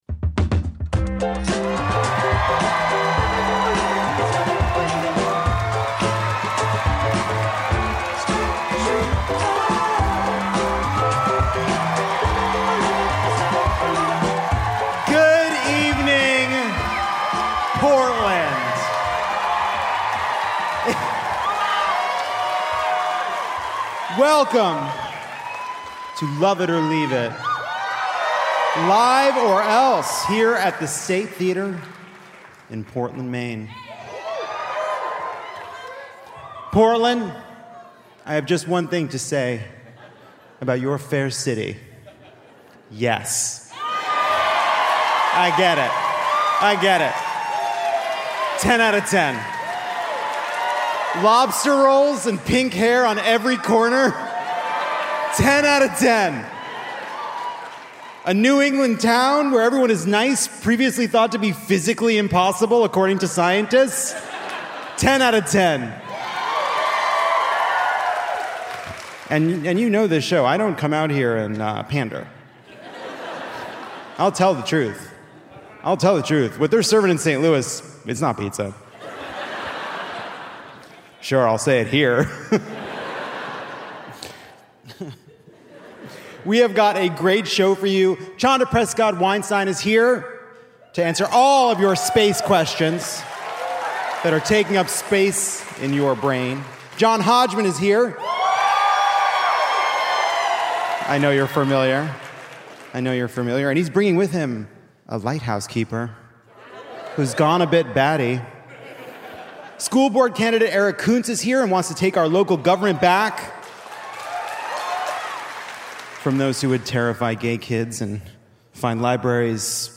Lovett or Leave It mentions the bisque during our wind-swept tour stop in Portland, Maine! Cosmologist Chanda Prescod-Weinstein has Lovett reaching for the stars, while a grizzled lighthouse keeper (John Hodgman, Judge John Hodgman live at Lincoln Center on June 29) laments how quickly conservatives have slipped into madness.